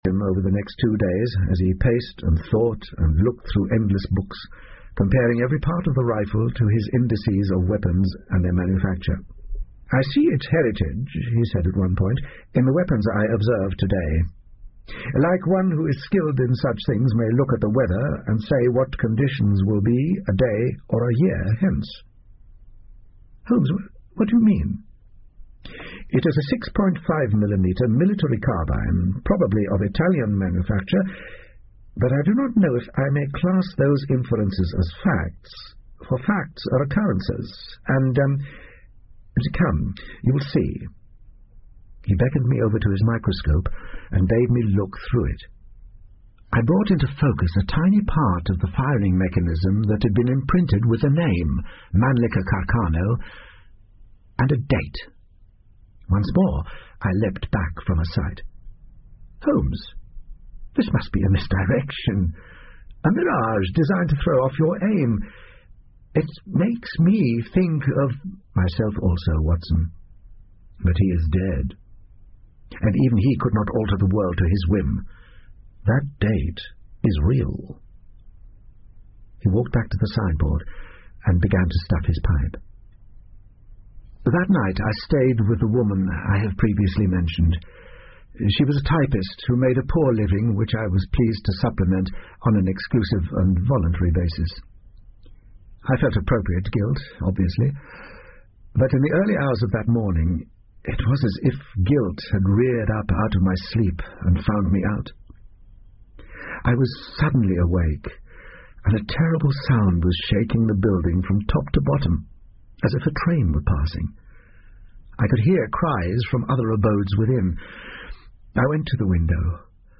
福尔摩斯广播剧 Cult-The Deer Stalker 3 听力文件下载—在线英语听力室